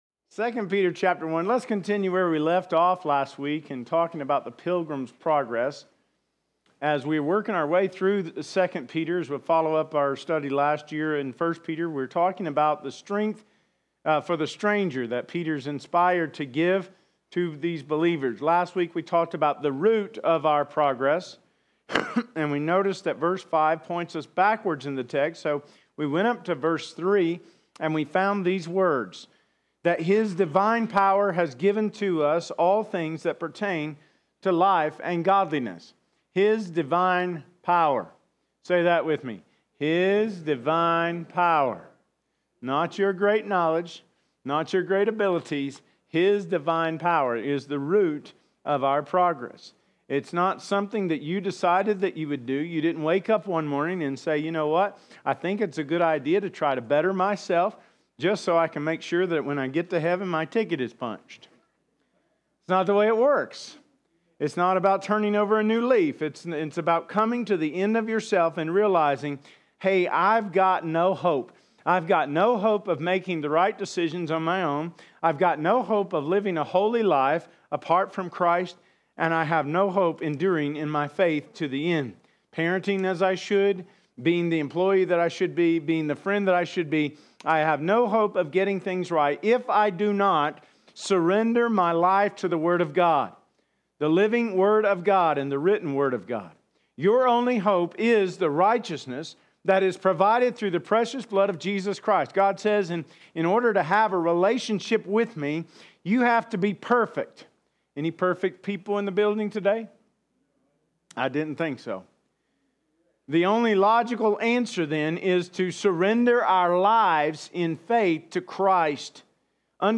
2025 Sermons - Smith Valley Baptist Church